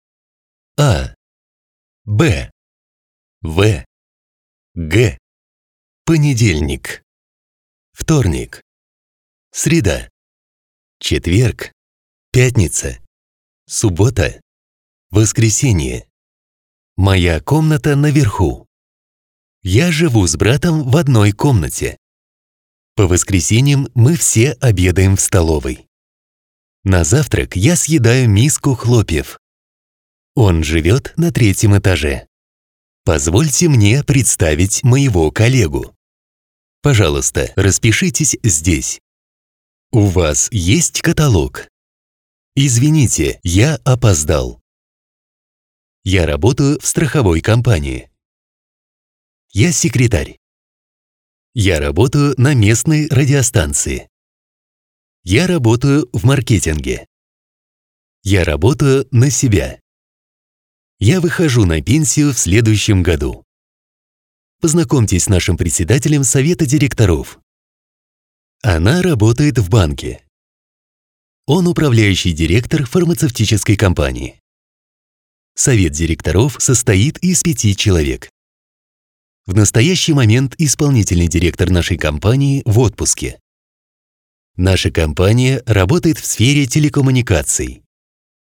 Sprechprobe: eLearning (Muttersprache):